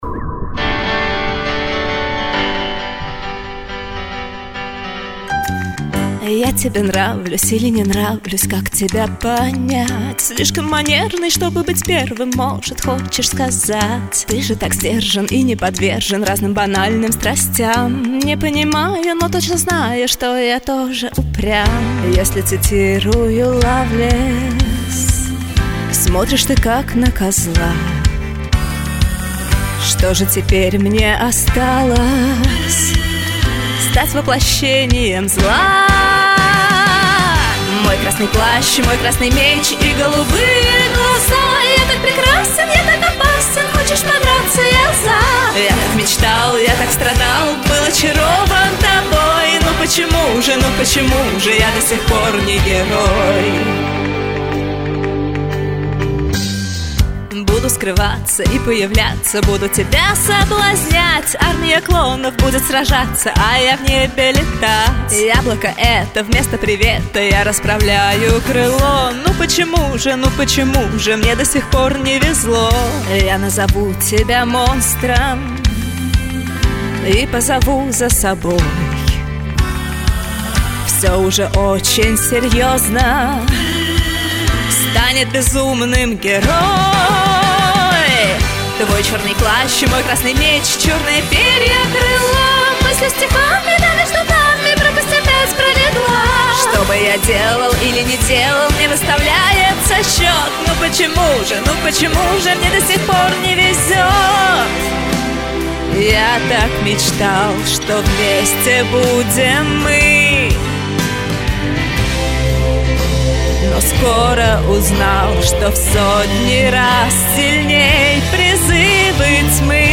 К гитаре :hlop::hlop::hlop: